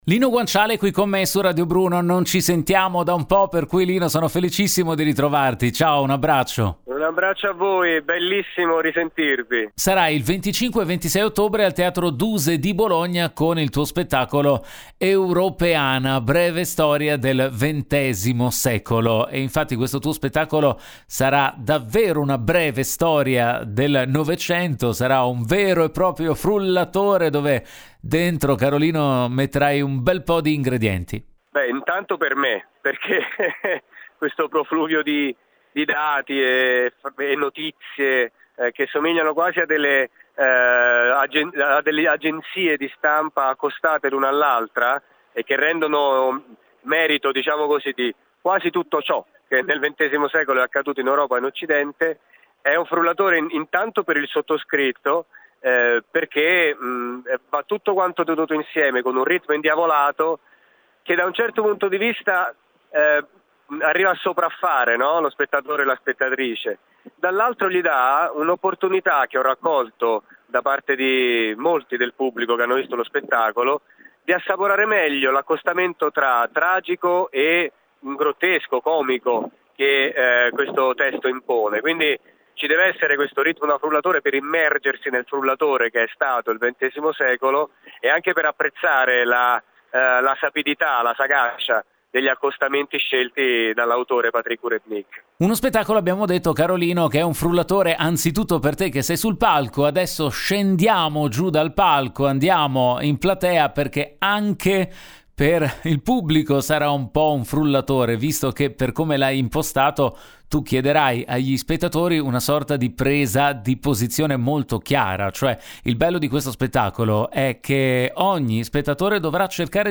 Home Magazine Interviste Lino Guanciale al Duse con “Europeana.